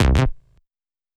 TI98BASS2 -L.wav